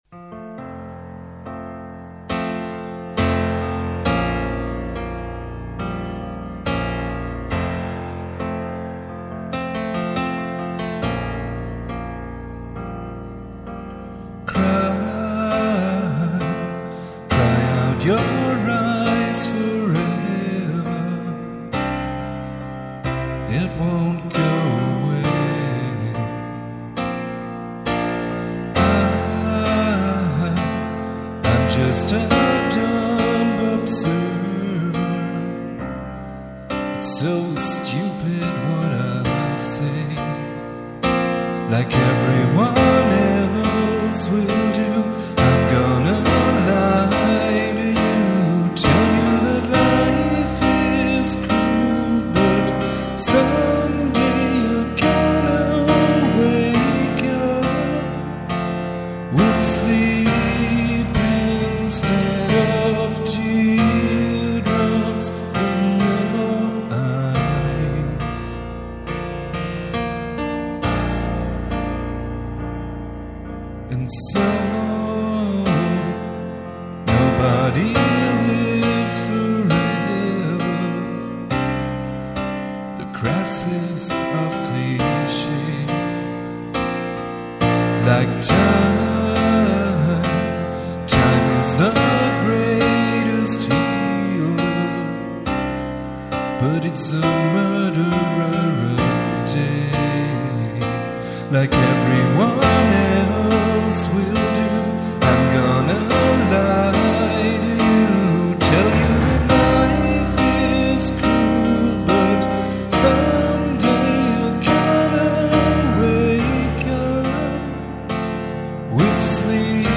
(Acoustic live)